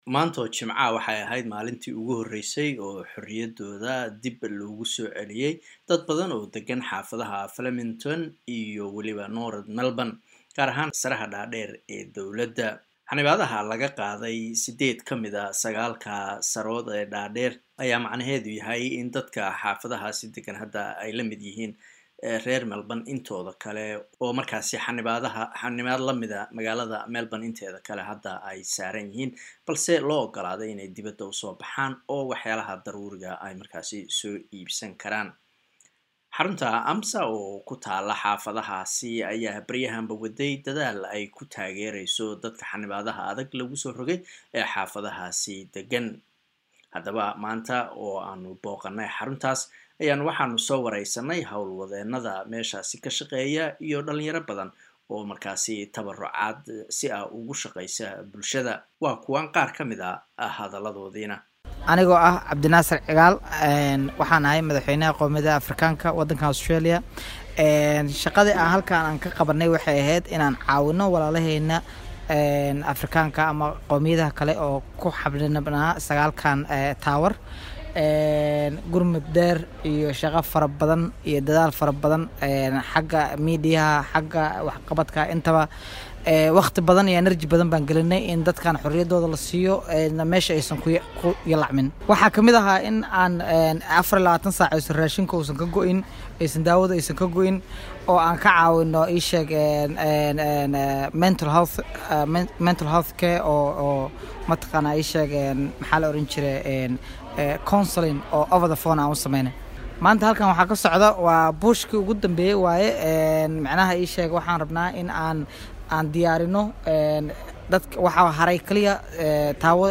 Waxaan waraysi la yeelanay qaar ka mid ah howl wadeenada iyo dadka tabarucaad uga shaqeeya xarunta AMSSA